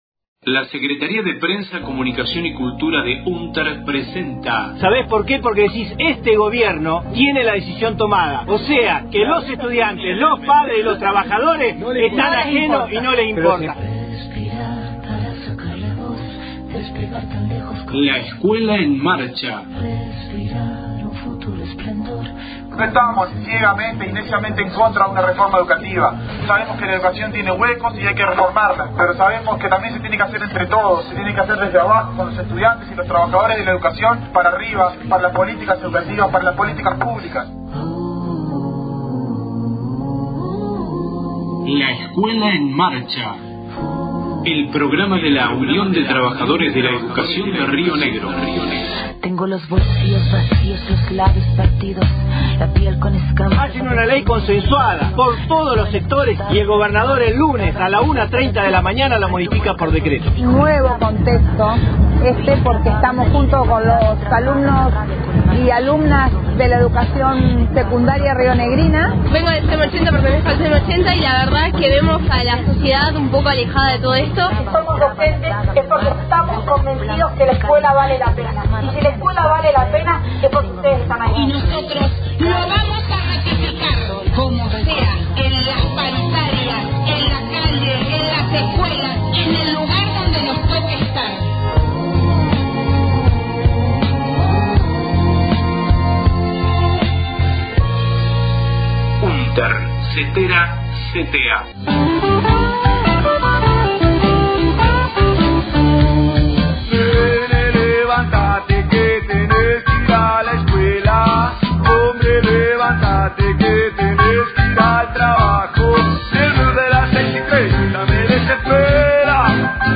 Congreso de Viedma 28/04/17 audio de intervención en apertura